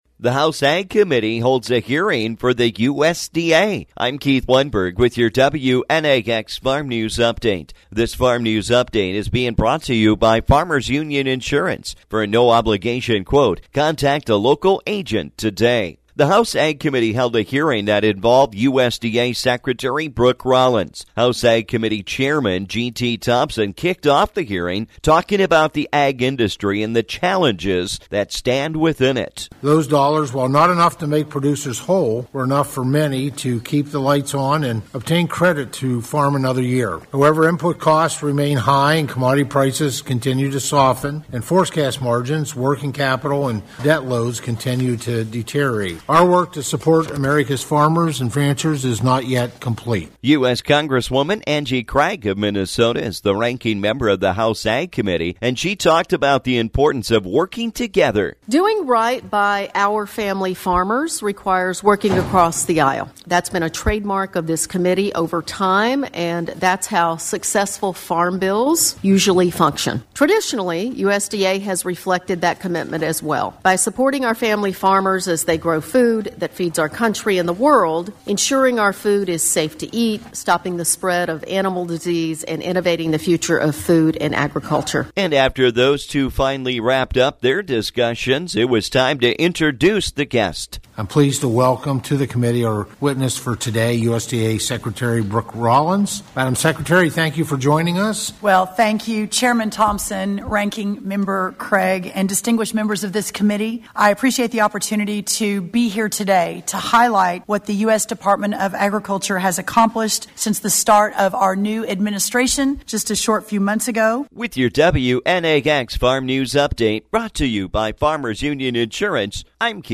The House Ag Committee held a hearing that involved USDA Secretary Brooke Rollins.